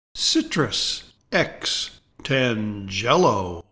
Pronounciation:
CI-trus X tan-GEL-o